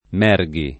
[ m $ r g i ]